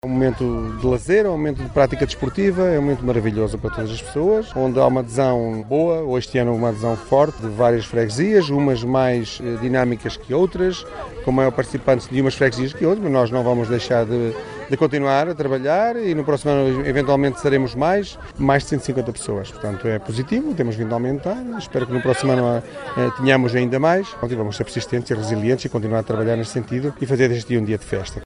Uma adesão positiva que o vice-presidente da autarquia de Macedo de Cavaleiros, Rui Vilarinho, espera que continue a aumentar: